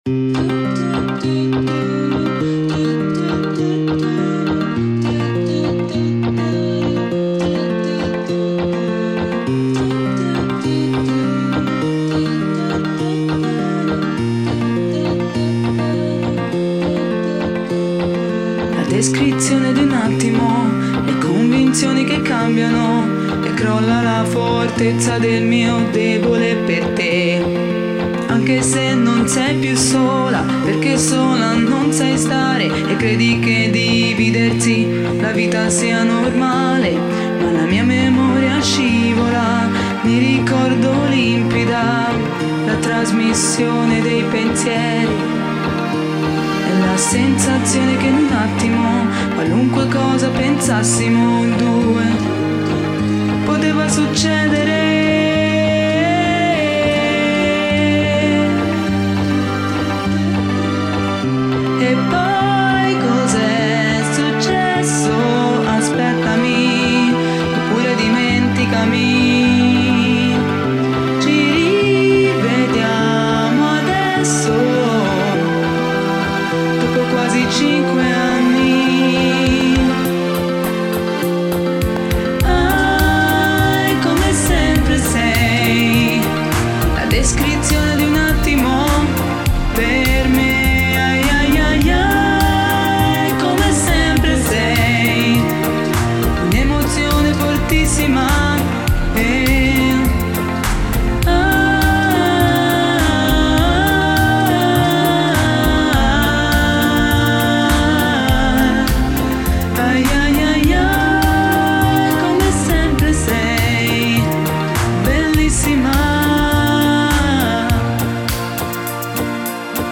con controcanti e cori "virtuali"